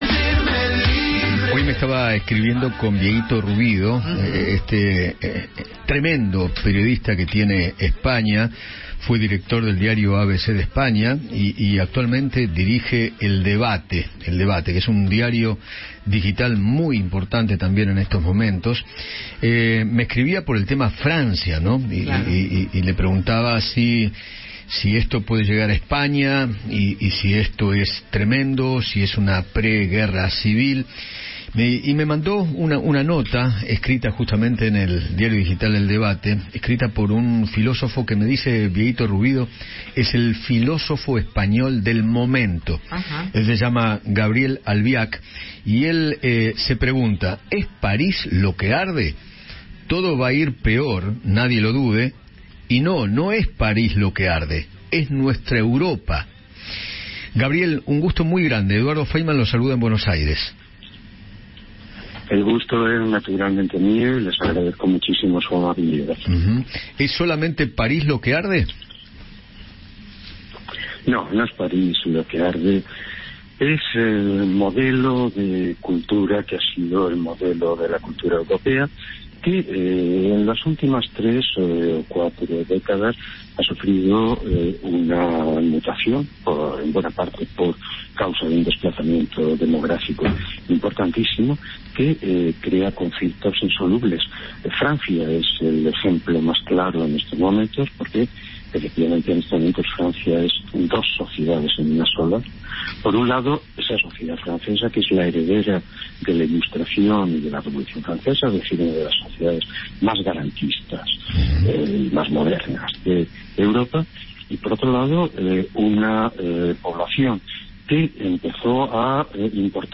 Gabriel Albiac, filósofo español, conversó con Eduardo Feinmann sobre las manifestaciones en Francia y sostuvo que “el modelo de la cultura europea ha sufrido una mutación en las ultimas tres o cuatro décadas”.